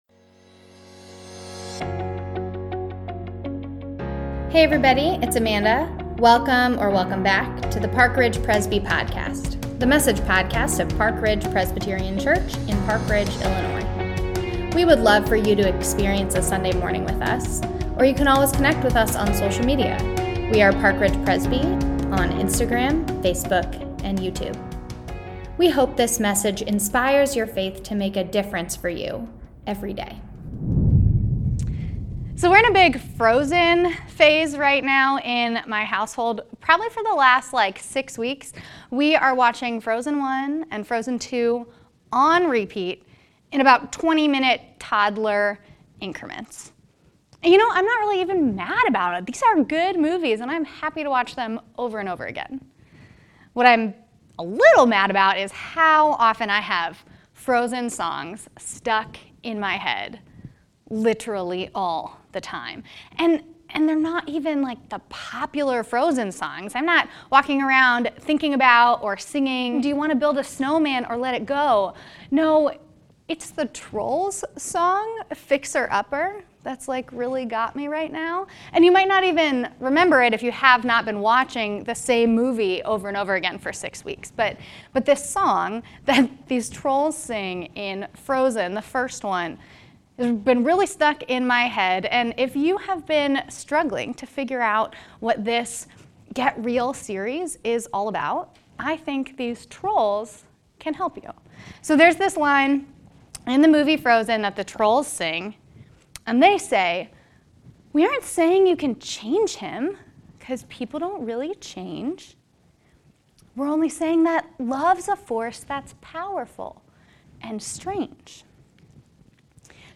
Get Real Week 4 | Online Worship 10am | March 27, 2022 | Lent 2022